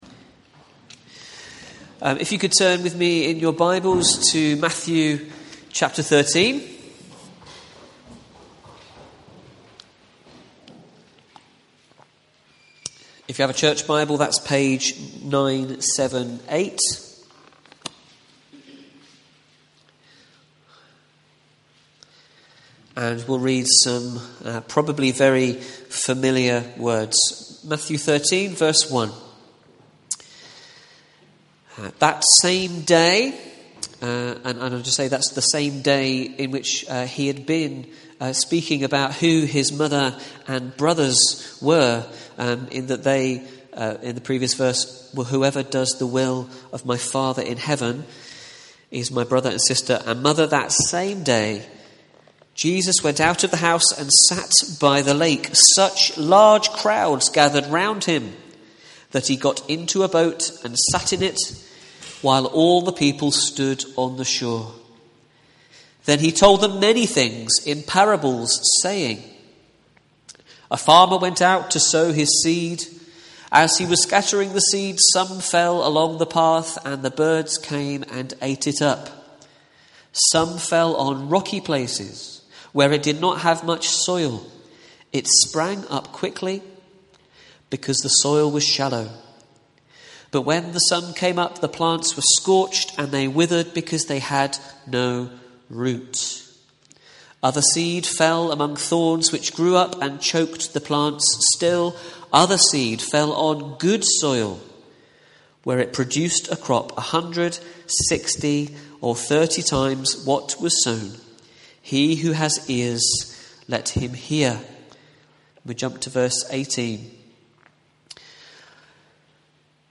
Today’s sermon covers the parable of the sower